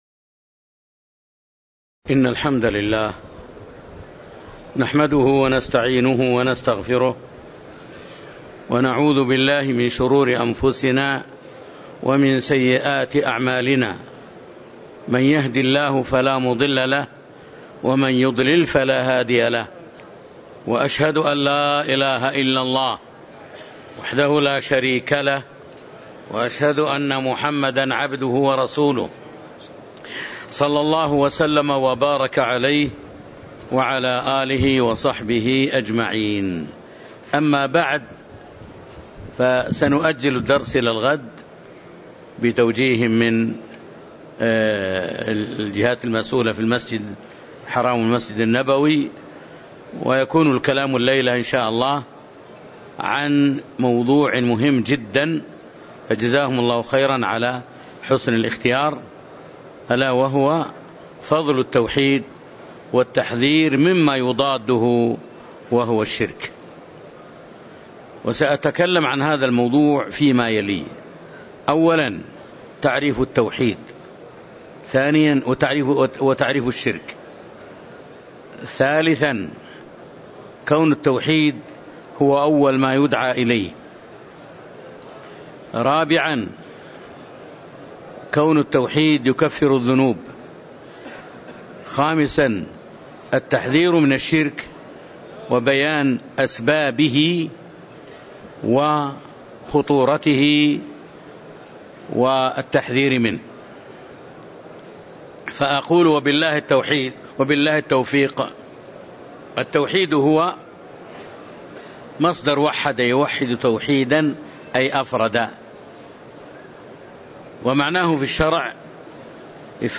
تاريخ النشر ٢١ ذو الحجة ١٤٤٤ هـ المكان: المسجد النبوي الشيخ